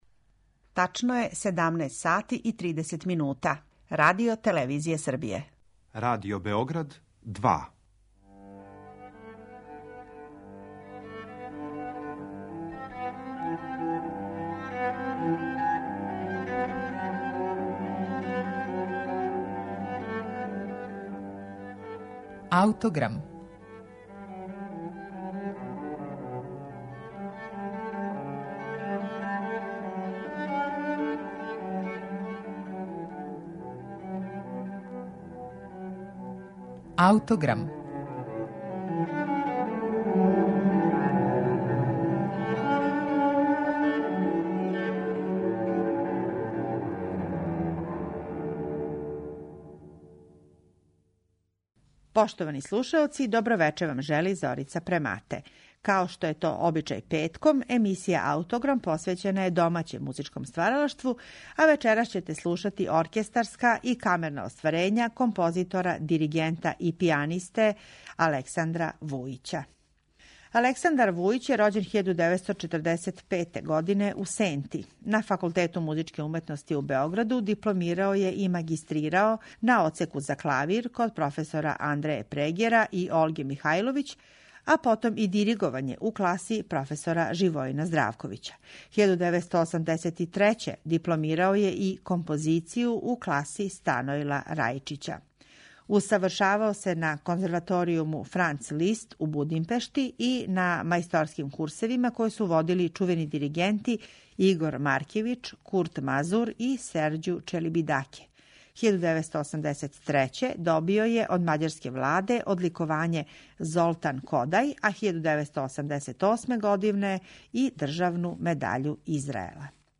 Гудачки квартет